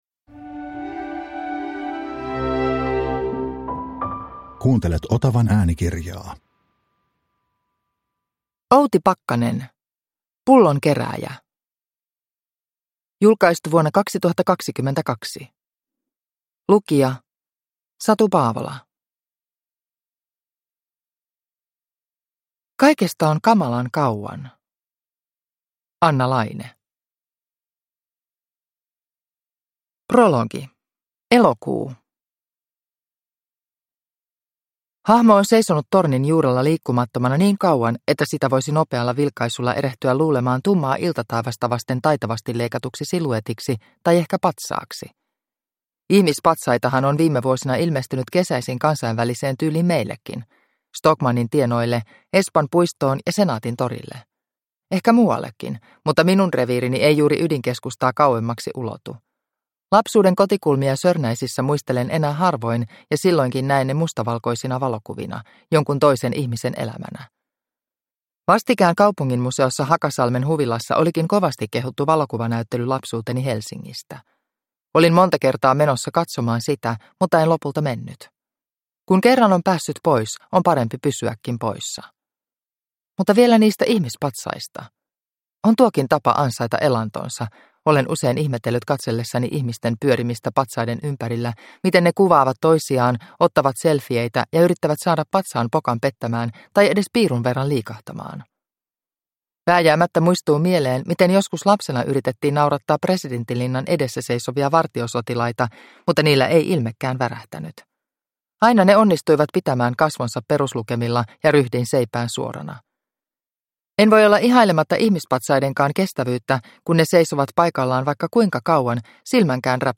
Pullonkerääjä – Ljudbok – Laddas ner